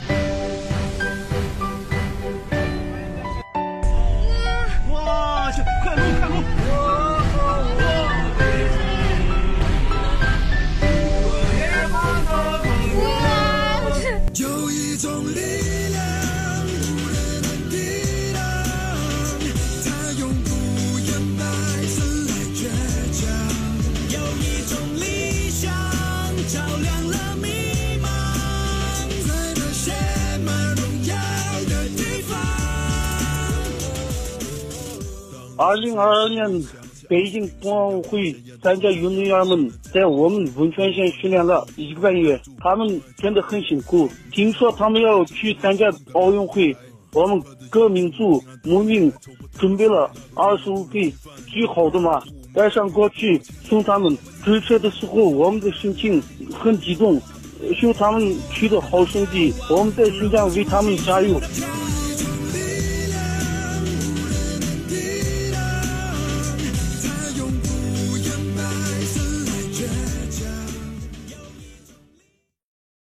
高举国旗骑马追赶车队
并大声呐喊助威